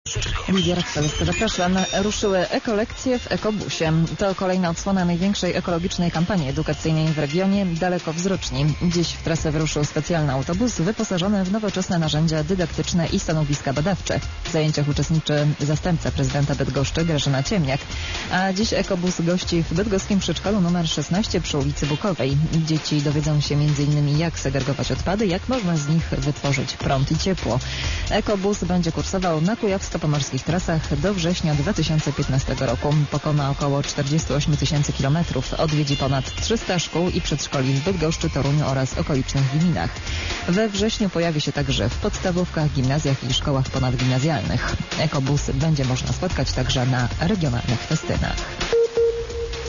Pobierz plik (radio_eska_bydgoszcz-2013_06_27_11_59_04-wiadomosci_lokalne_.wav)radio_eska_bydgoszcz-2013_06_27_11_59_04-wiadomosci_lokalne_.wav[ ]0 kB